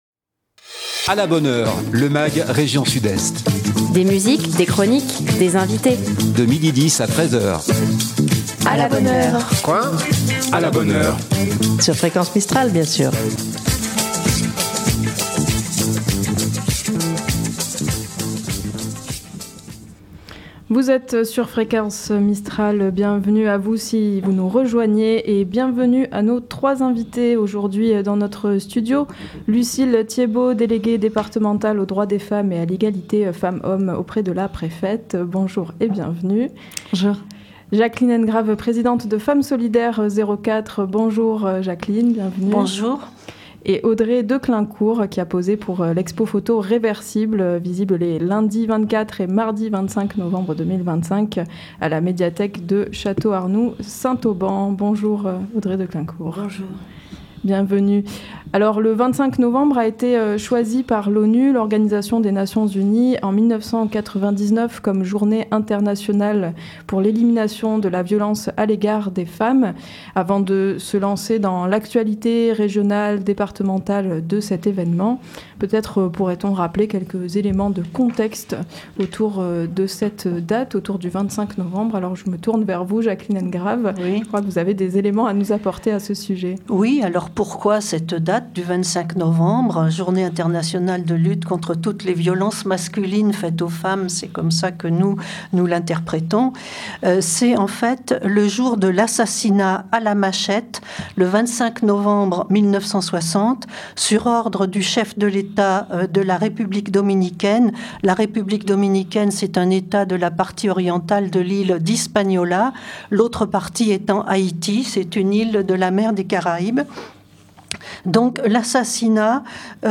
Quelques rappels historiques sur la date du 25 novembre, des exemples concrets, mais aussi un éclairage sur les différents types de violences, visibles comme invisibles : les invitées de ce magazine apportent chacune leur regard et leur part d'expertise sur les violences faites aux femmes.